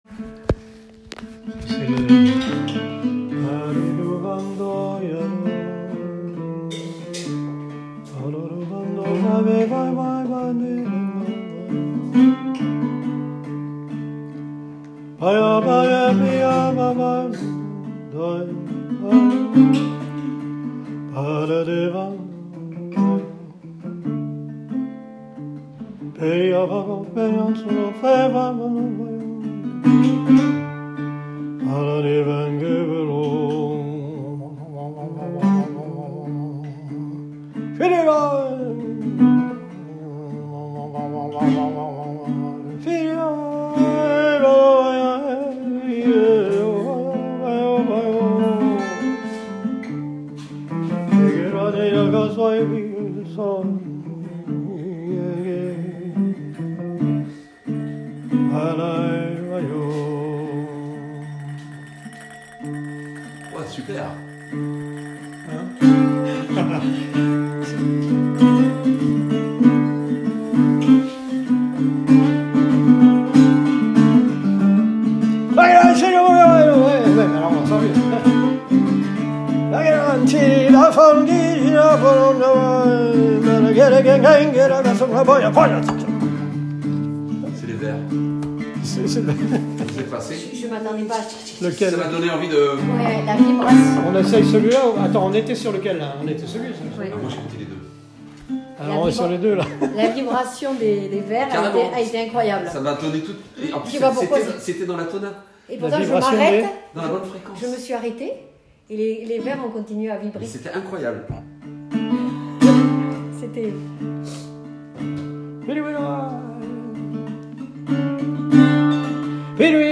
• Mise en voix et guitare de vins de Château SIAURAC – Lalande de Pomerol